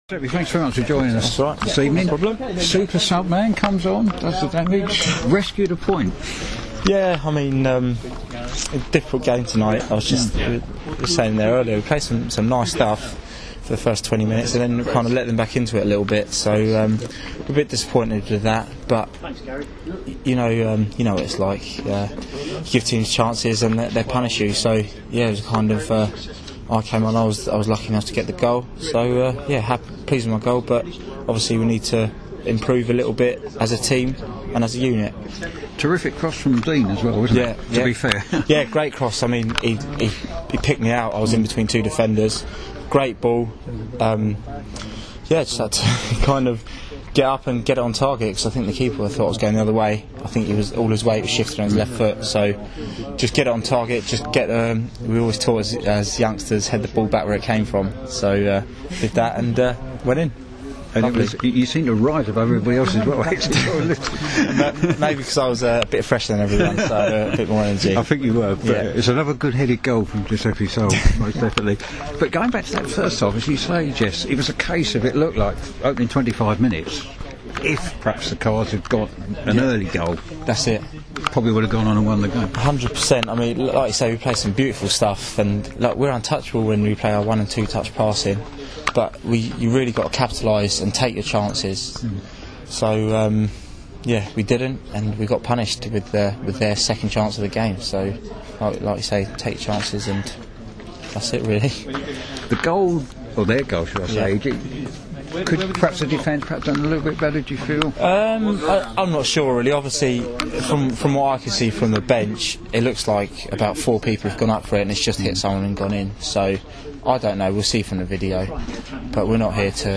speaking after Woking's 1-1 draw with Dartford